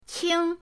qing1.mp3